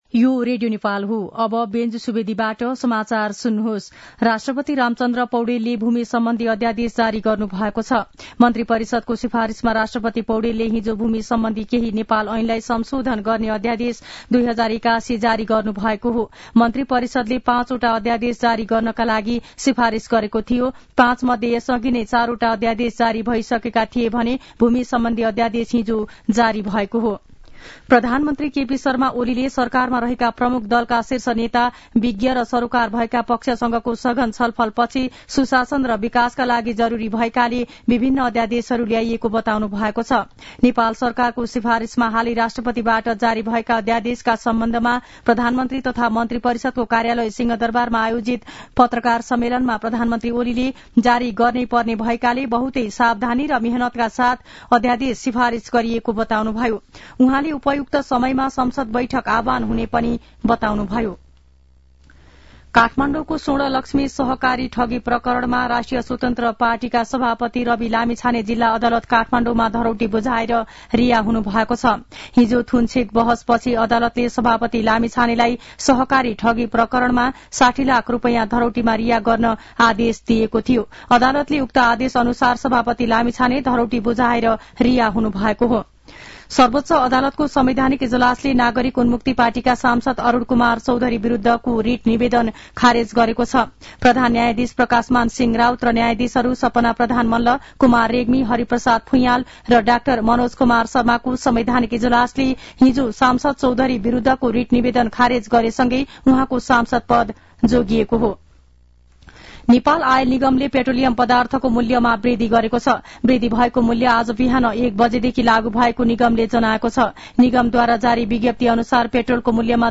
मध्यान्ह १२ बजेको नेपाली समाचार : ४ माघ , २०८१